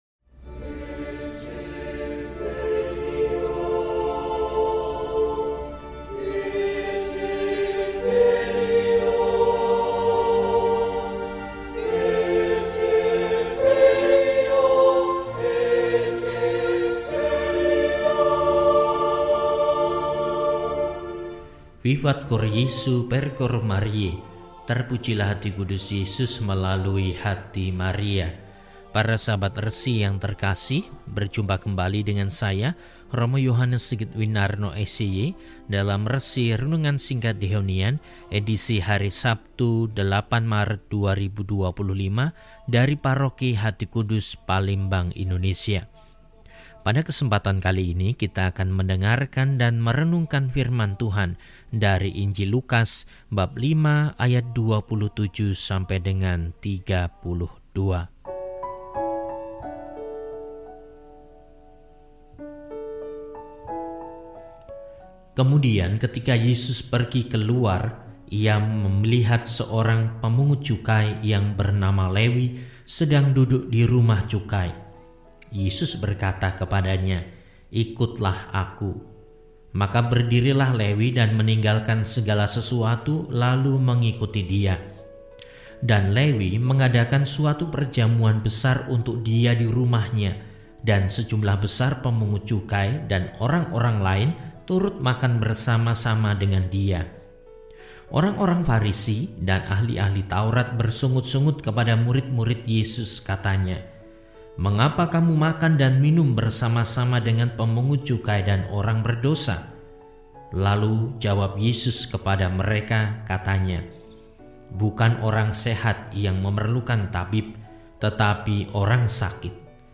Sabtu, 08 Maret 2025 – Hari Sabtu sesudah Rabu Abu – RESI (Renungan Singkat) DEHONIAN